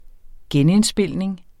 Udtale [ ˈgεn- ]